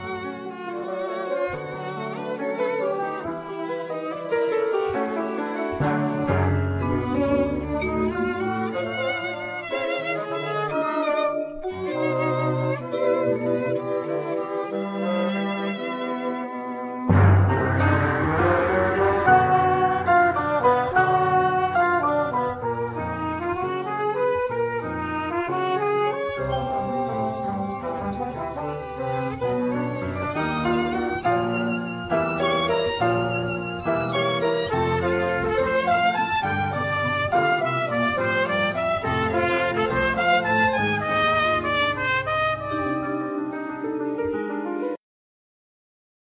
Violin
Basson
Piano
Doublebass
Drums,Percussions
Vibes,Marimba,Percussions
Vocals
Trumpet,Flugelhorn
Trombone
Bass Clarinat